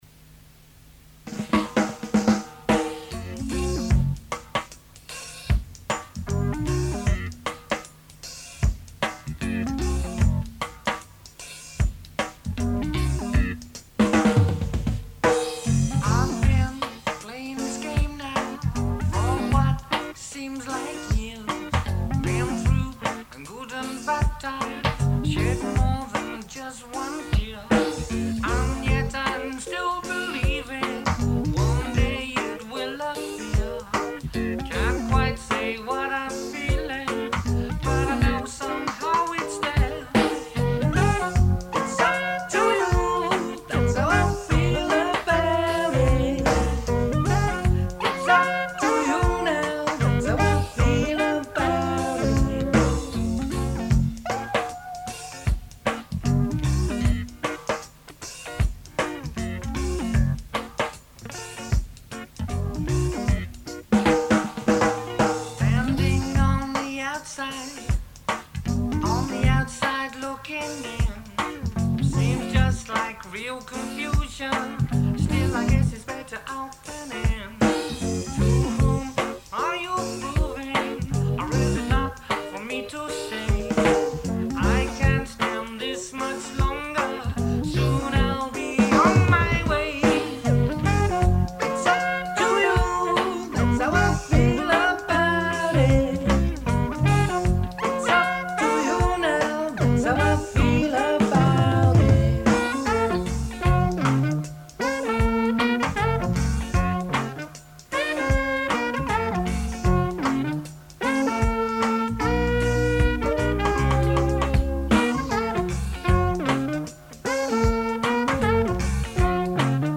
being played live in the studio, no multi-tracking.